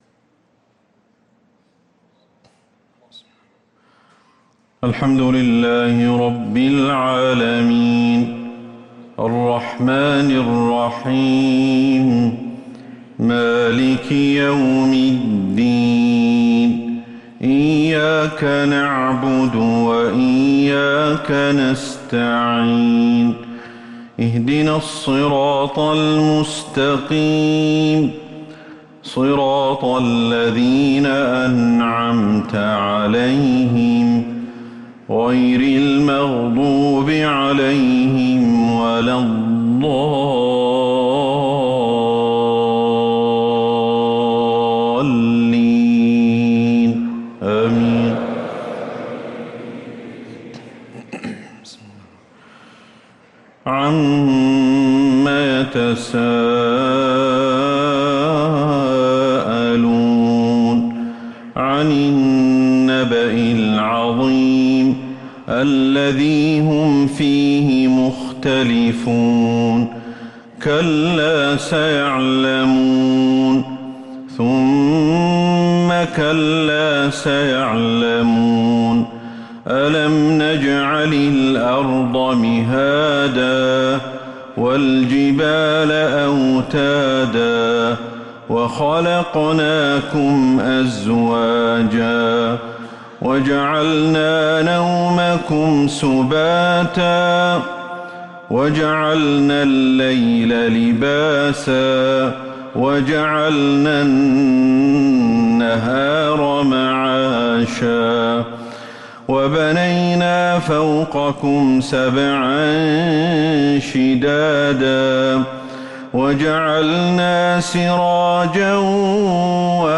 صلاة الفجر للقارئ أحمد الحذيفي 15 ذو الحجة 1444 هـ